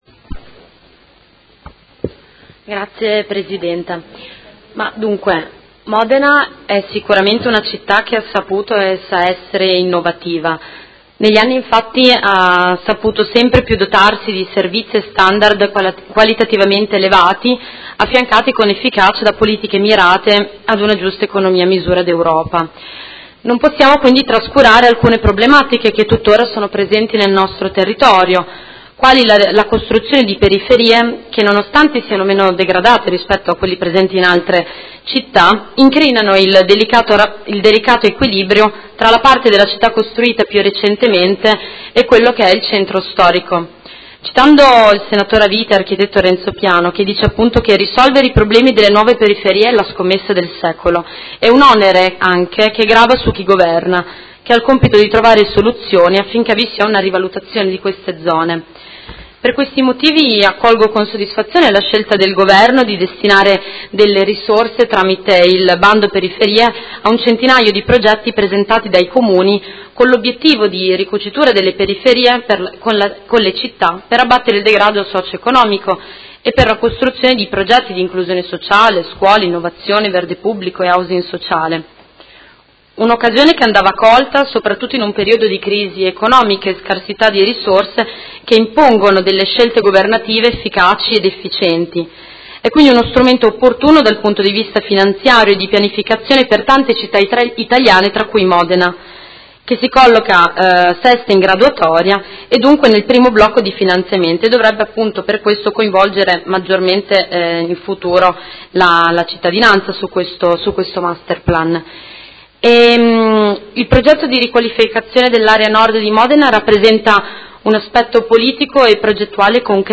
Seduta del 25/05/2017 Dibattito su Delibera Linee di indirizzo per il riordino funzionale e morfologico dell’ Area urbana a Nord di Modena “Fascia ferroviaria” – Approvazione Masterplan e su Ordine del giorno 80690 avente per oggetto: Progetto Periferie, rigenerazione e innovazione.